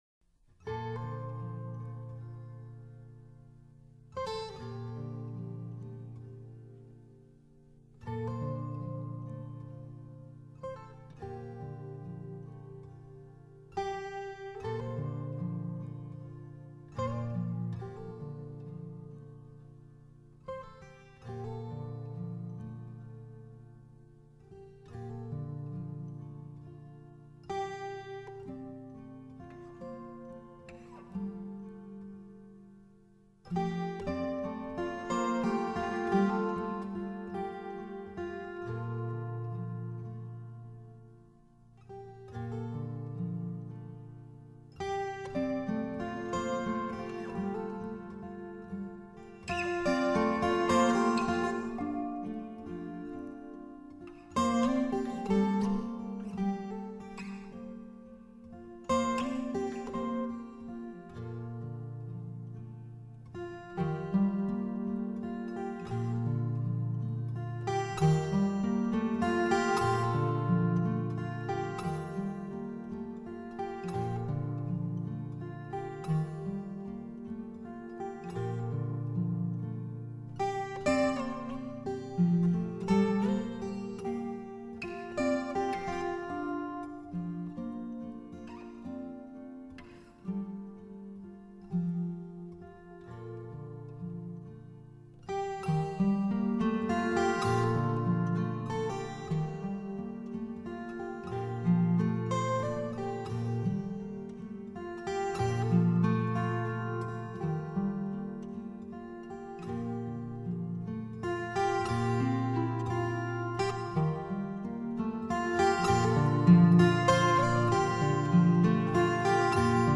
音乐风格： New Age，Guitar
常常在想，究竟，是什么样的一个人，才会写出如此轻柔、安详、静美的音乐，